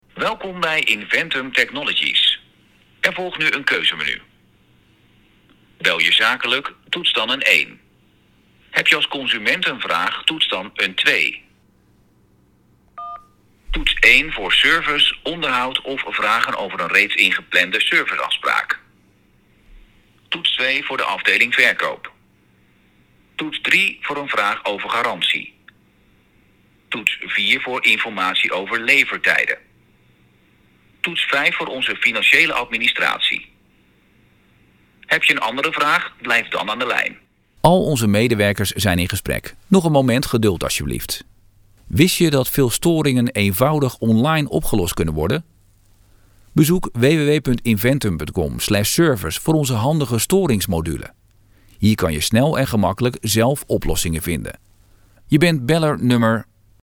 Voice
Natural, Distinctive, Accessible, Reliable, Friendly
Telephony